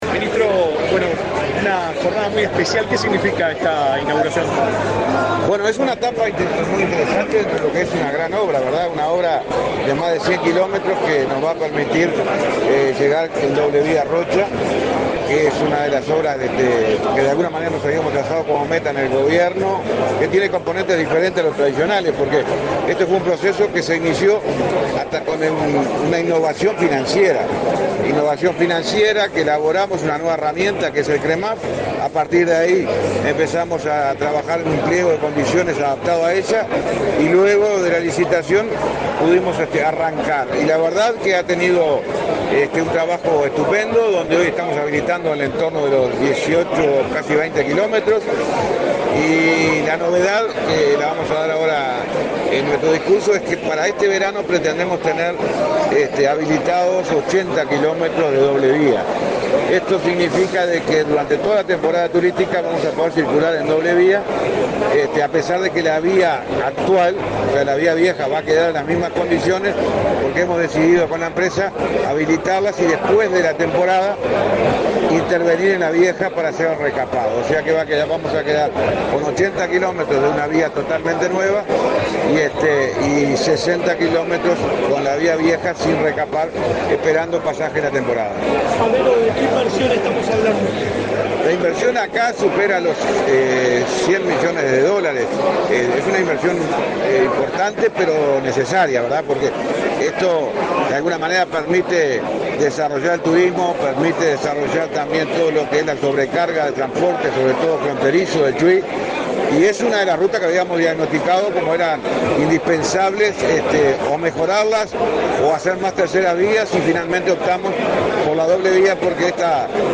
Declaraciones a la prensa del ministro de Transporte y Obras Públicas, José Luis Falero
Declaraciones a la prensa del ministro de Transporte y Obras Públicas, José Luis Falero 24/10/2024 Compartir Facebook X Copiar enlace WhatsApp LinkedIn Tras la inauguración de obras en la ruta n.° 9, entre los departamentos de Maldonado y Rocha, este 24 de octubre, el ministro de Transporte y Obras Públicas, José Luis Falero, realizó declaraciones a la prensa.